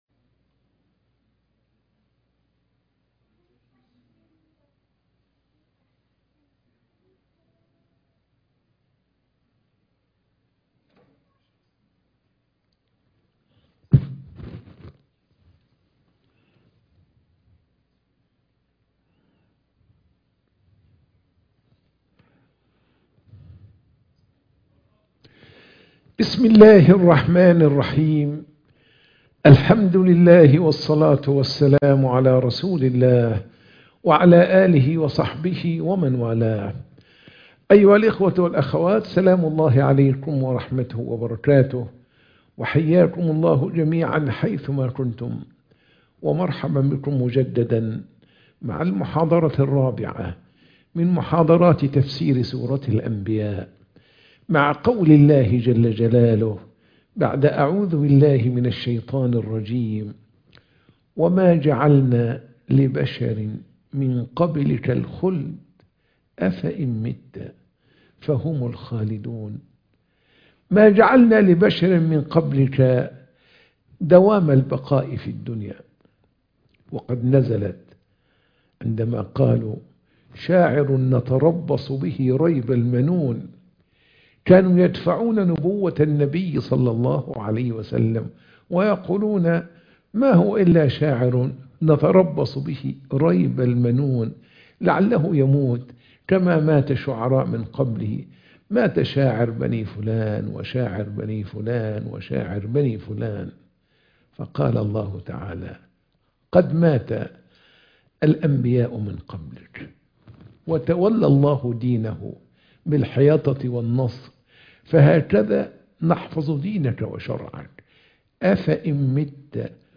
محاضرة التفسير - سورة الأنبياء - المحاضرة 4